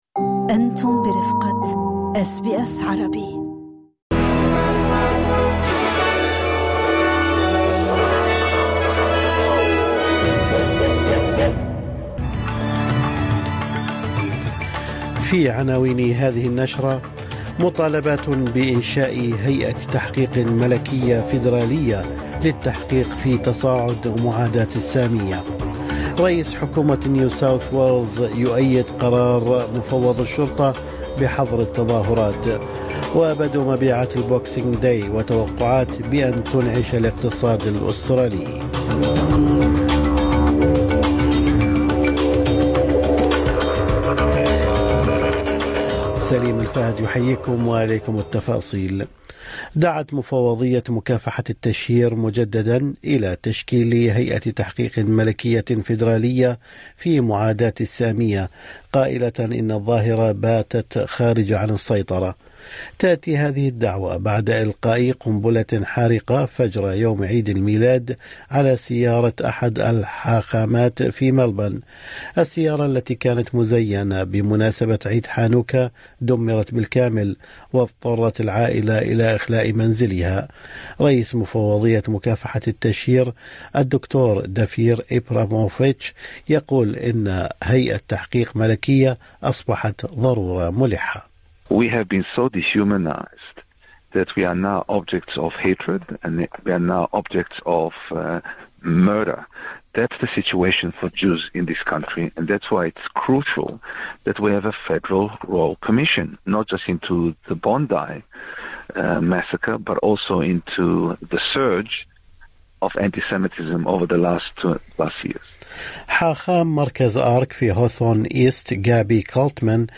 نشرة أخبار الصباح 26/12/2025